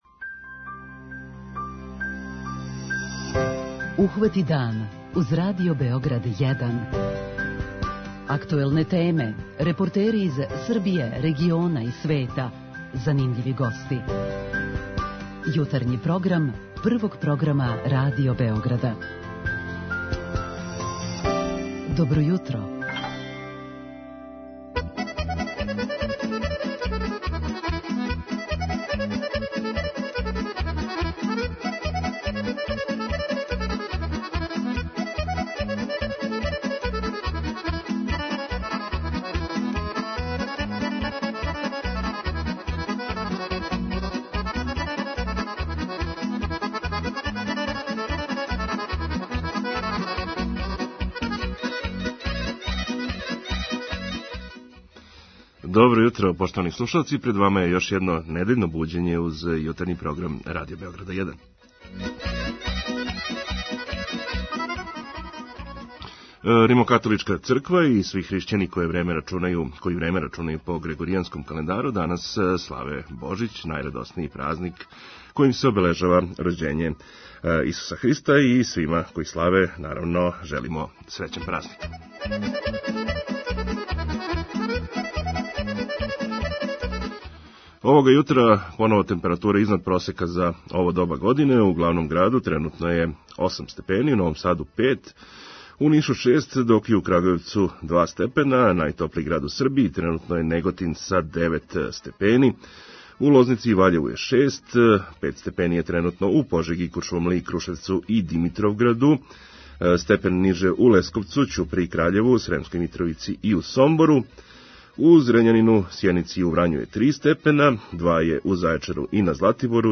И овог јутра чућете сервисне информације и најновије вести из спорта.